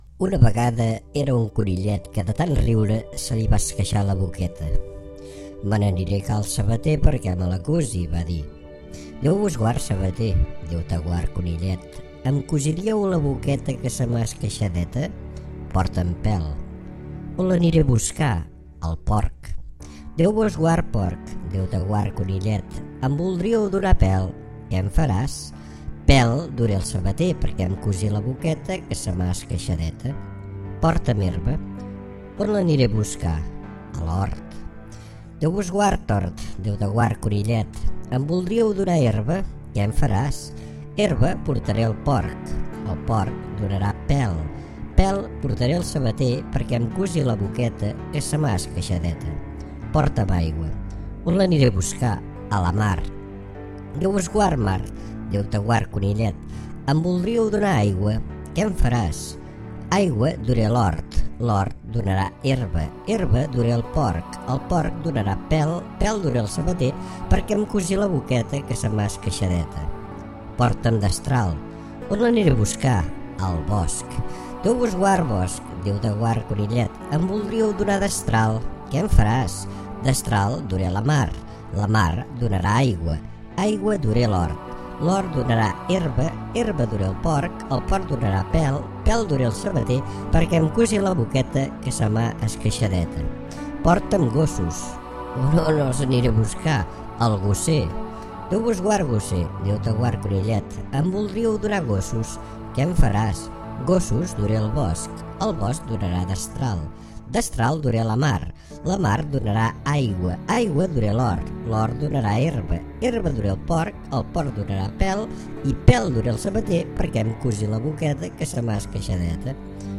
Rondalla El conillet [Versi� narrada en format mp3]